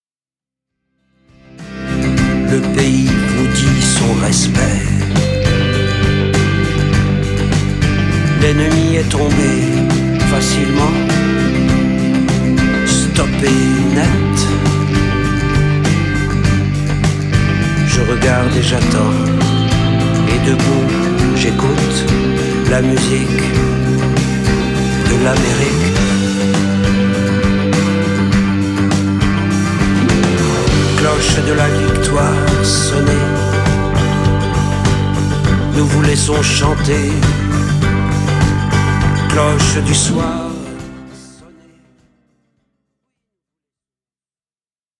Sa voix qui chante et déclame nous interpelle.